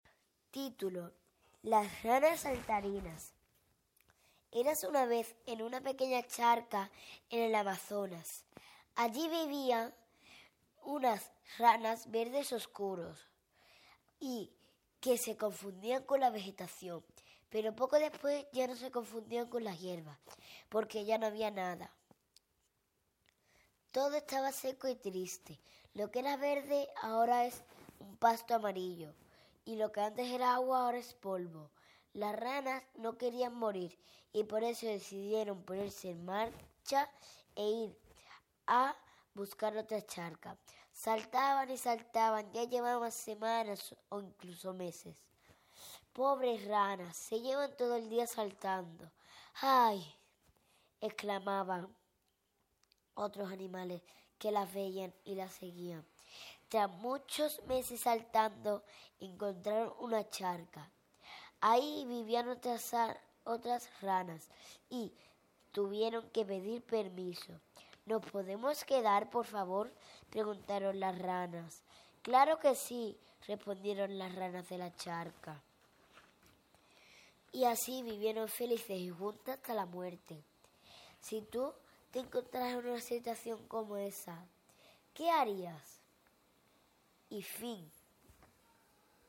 cuento